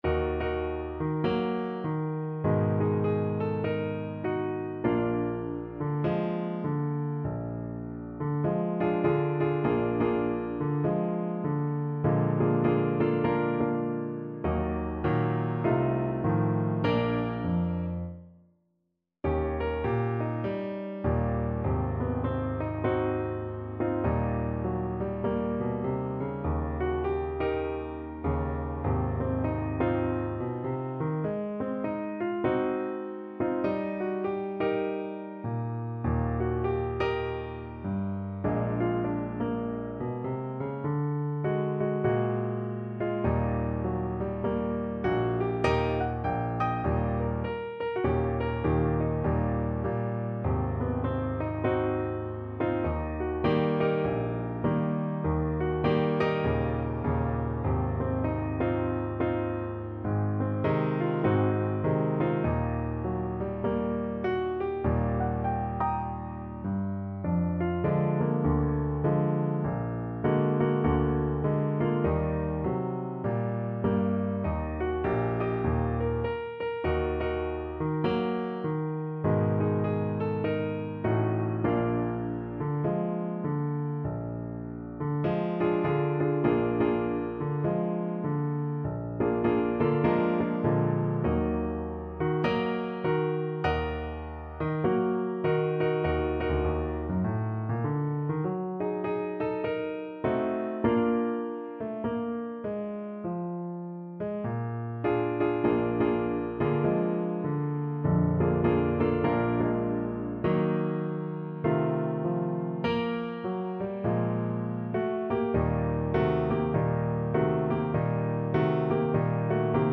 Jazz (View more Jazz Tenor Saxophone Music)